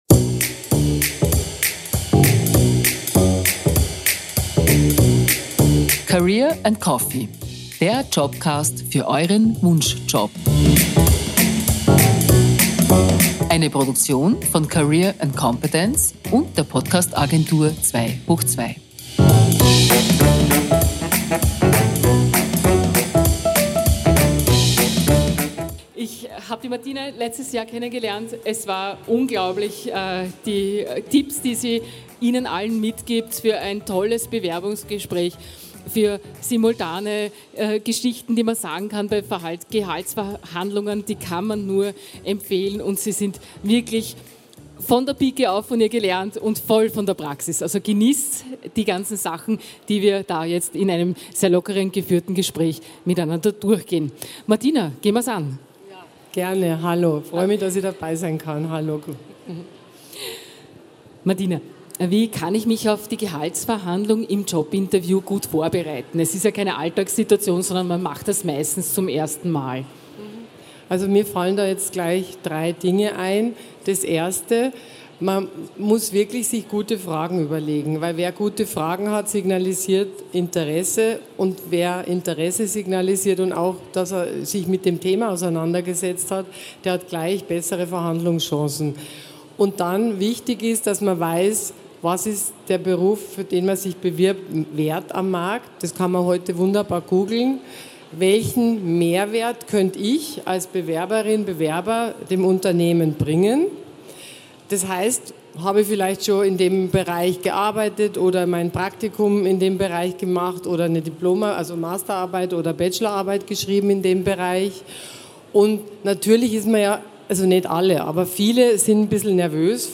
In dieser Podcast-Folge werden unterschiedliche Perspektiven eingebracht, um eine fundierte Entscheidung für Deine Gehaltsverhandlung zu ermöglichen. Livemitschnitt von der career & competence 2023 in Innsbruck, am 26. April 2023.